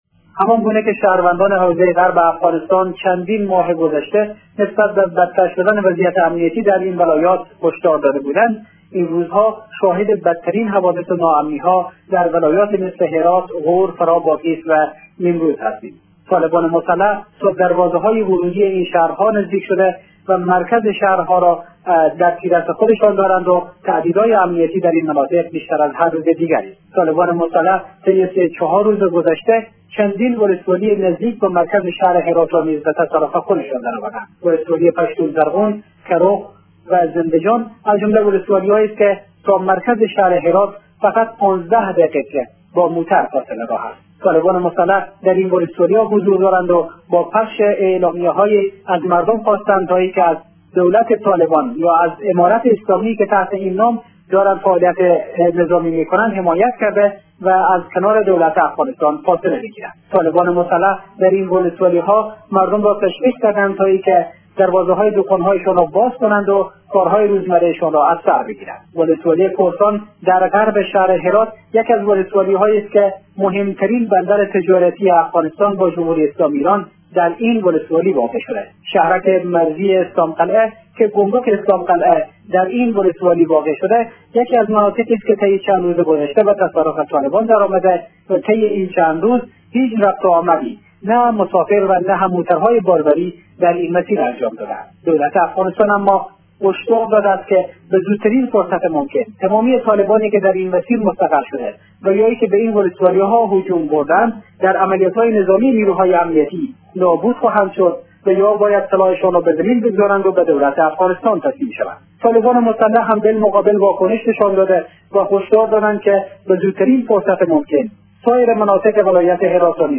گزارش تکمیلی از وضعیت امنیتی غرب افغانستان